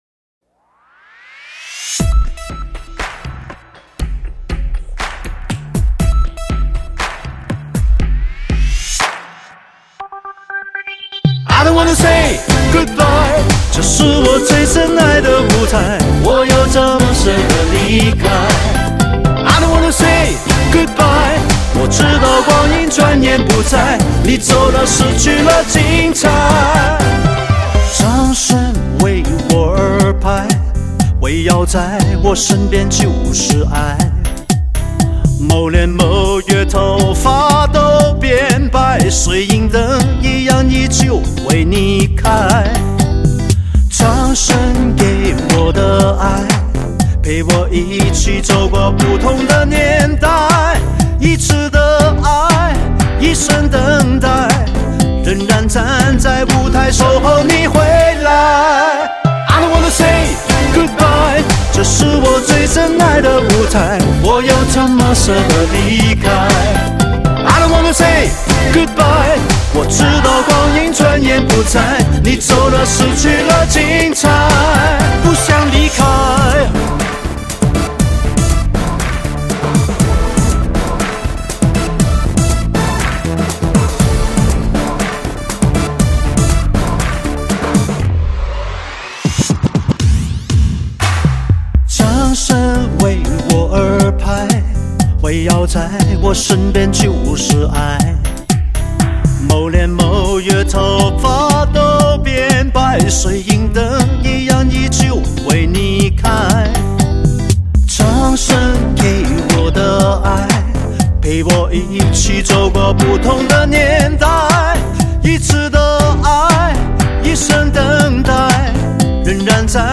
流行情歌，原创流行音乐汇辑，绝对时尚前线